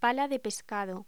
Locución: Pala de pescado